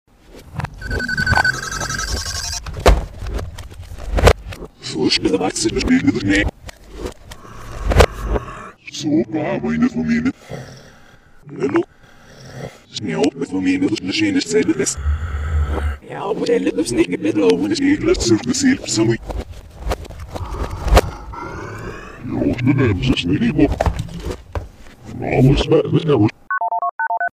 By reversing the soundtrack, the following message is heard: